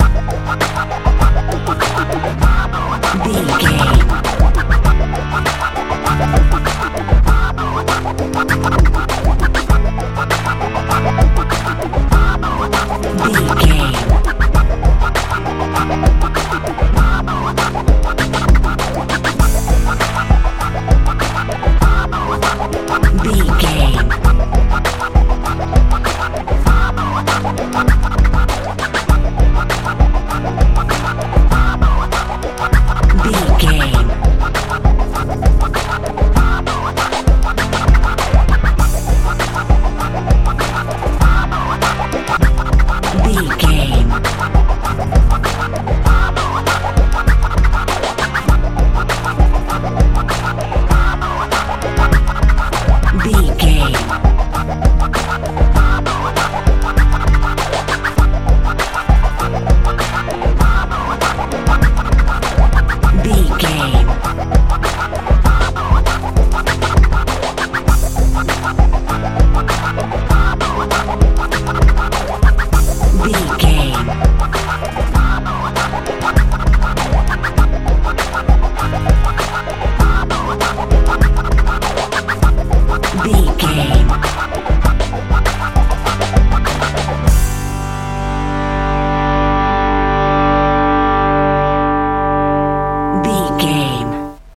hip hop feel
Ionian/Major
A♭
groovy
funky
electric guitar
bass guitar
drums
80s
90s
strange